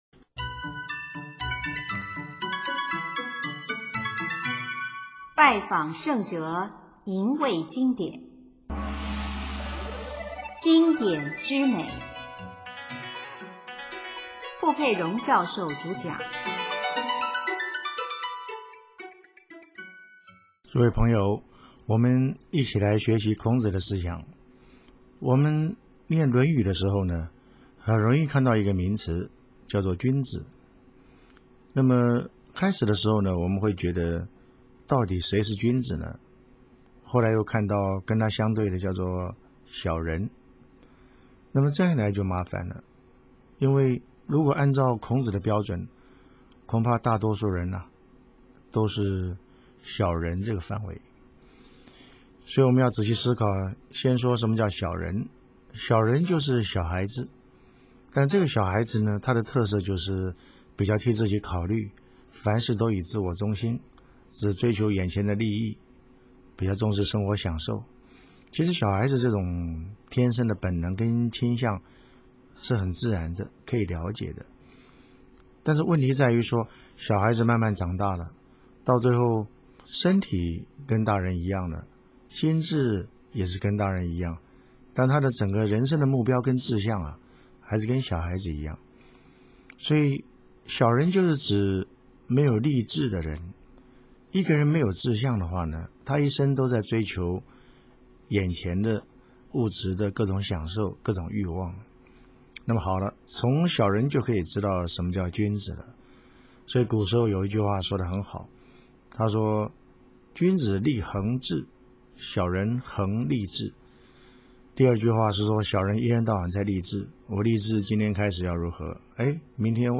主讲：傅佩荣教授